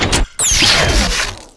switchon.wav